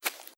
Grass Step 02.wav